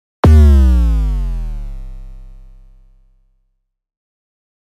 game-over.mp3